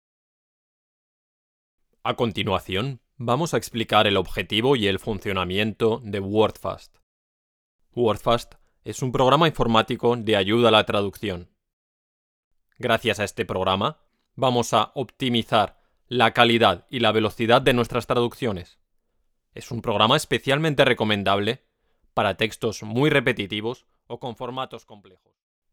I deliver best results with my very confident, smooth and professional tone for narration and technical copy. Also, I sound friendly, warm, sincere, honest and natural.
kastilisch
Sprechprobe: eLearning (Muttersprache):